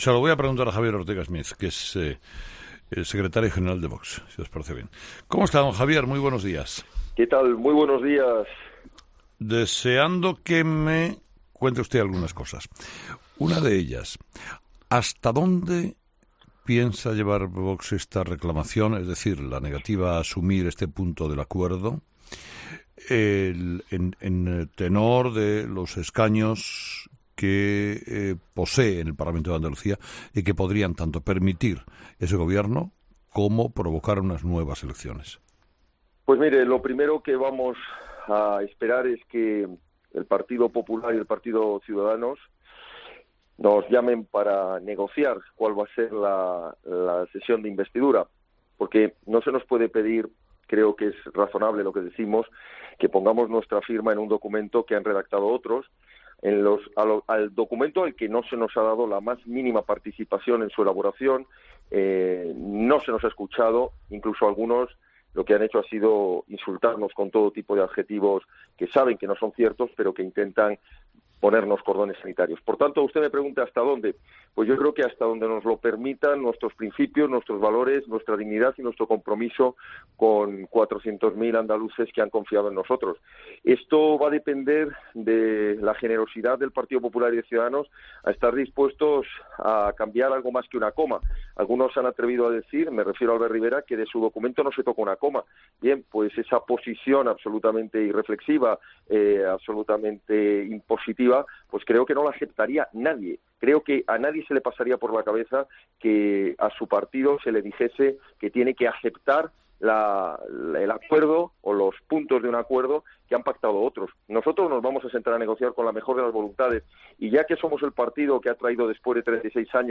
Entrevista completa de Herrera a Javier Ortega Smith (Vox)